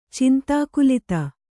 ♪ cintākulita